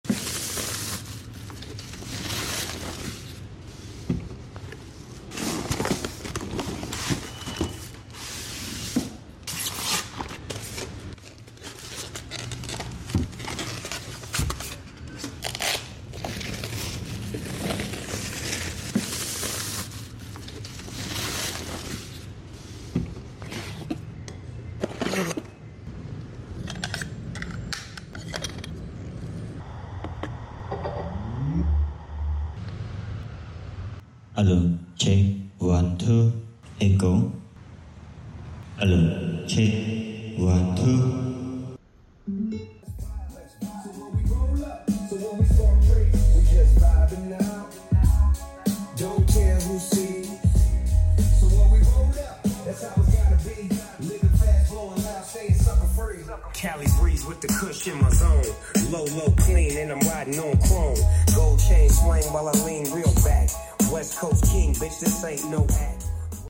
Mini Portable Bluetooth Karaoke Speaker sound effects free download
Mini Portable Bluetooth Karaoke Speaker – JBL PartyBox Encore 2 with Dual Wireless Mics | Sound Testing